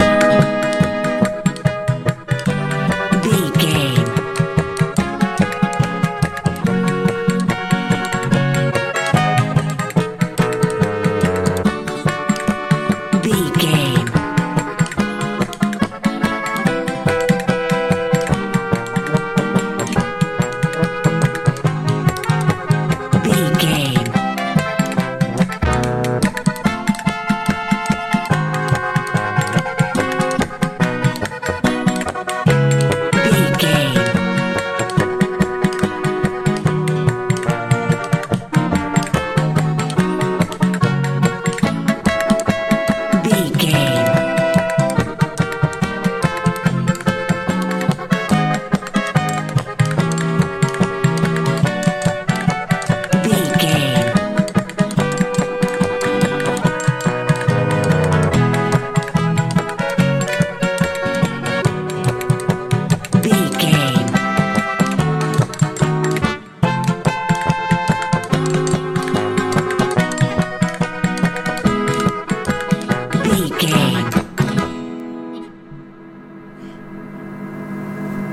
Ionian/Major
A♭
fun
playful
accordion
horns
bass guitar
drums